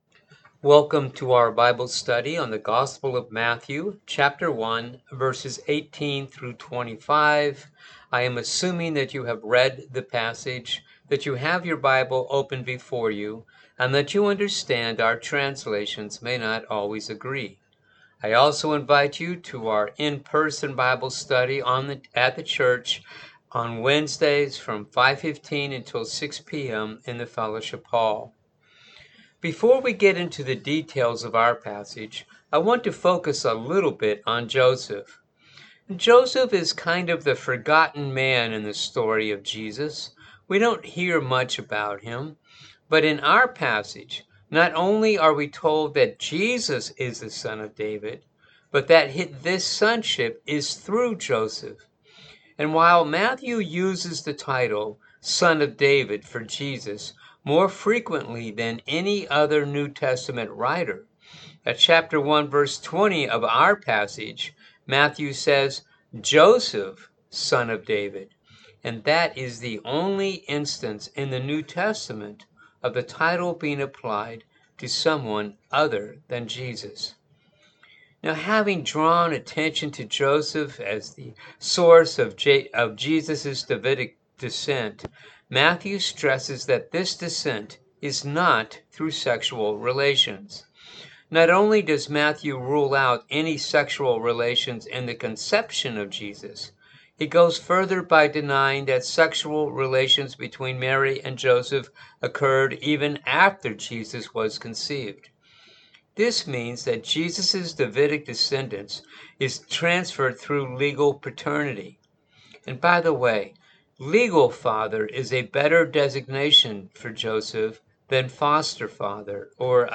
Bible Study for the December 18 Service